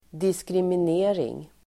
Uttal: [diskrimin'e:ring]